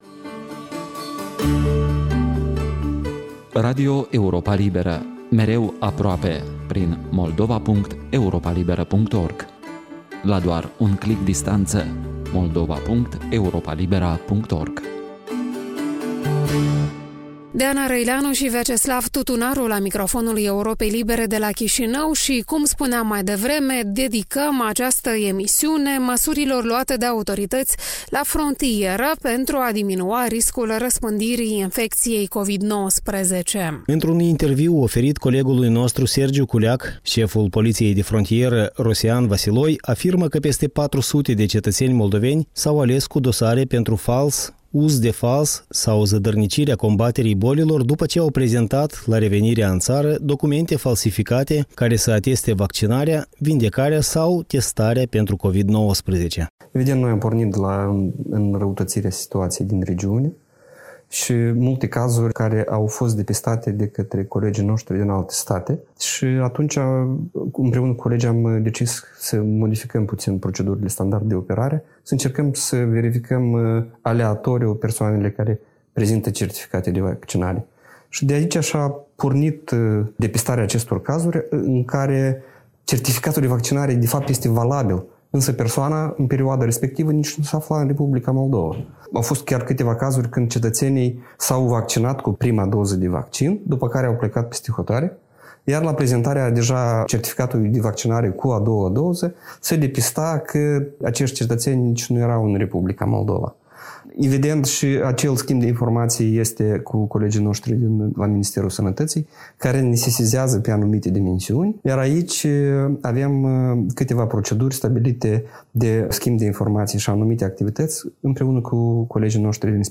Interviu cu Rosian Vasiloi, șeful Poliției de Frontieră, despre certificate Covid false